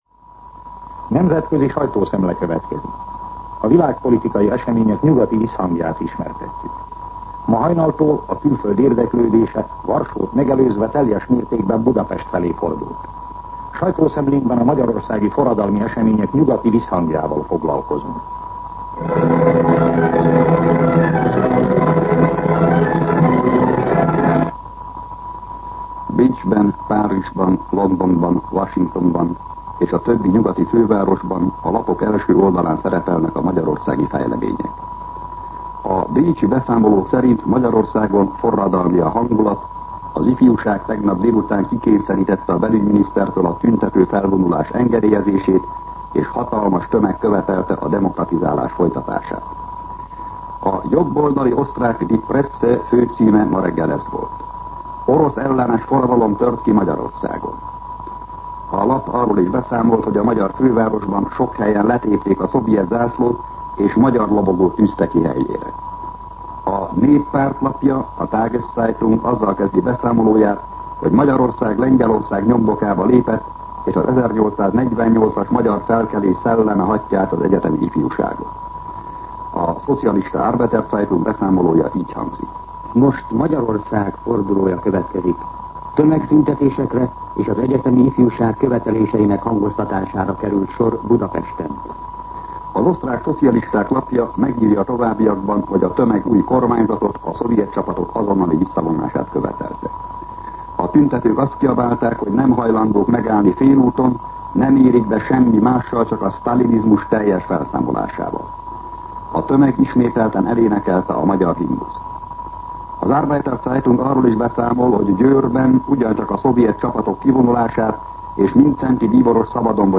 Nemzetközi sajtószemle